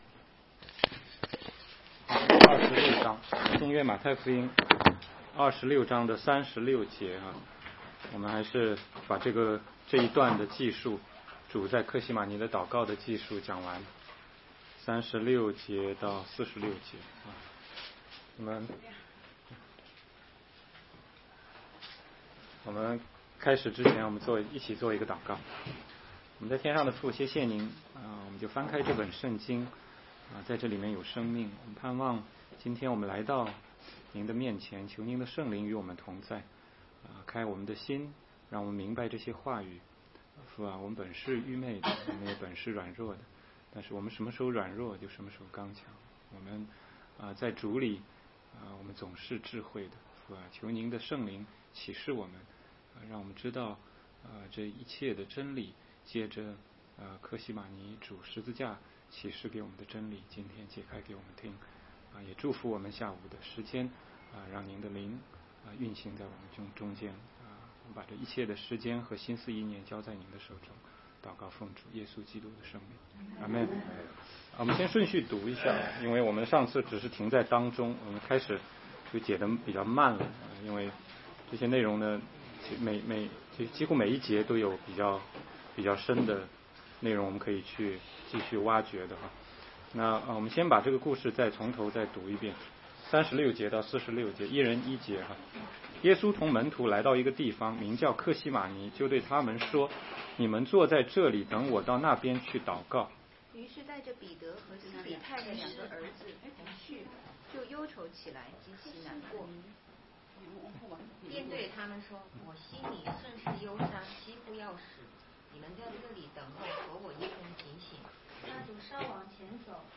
16街讲道录音 - 马太福音